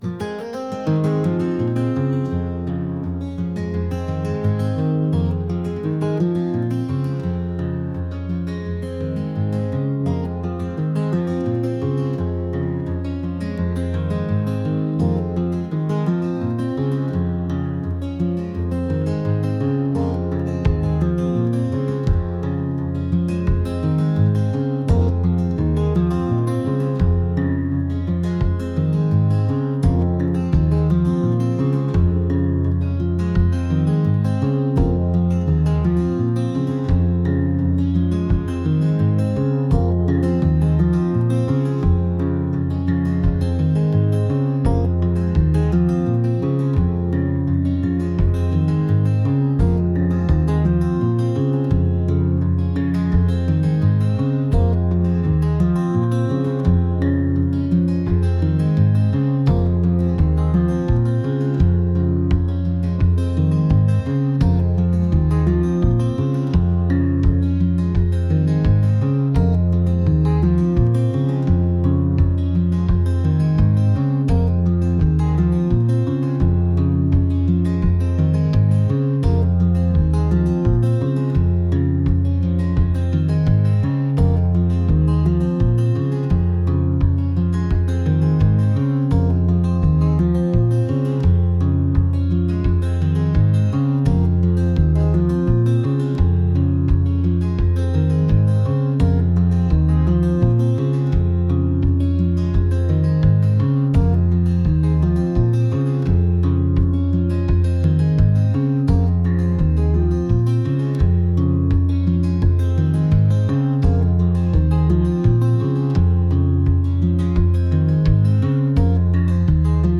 folk | indie | pop